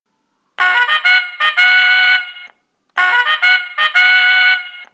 Non poteva mancare anche lo strillone, la trombetta che per tutto l'anno ha accompagnato le partite dei salesiani.
Clicca qui sotto per ascoltare lo "strillone" che ha accompagnato la cavalcata del Don Bosco 2004/2005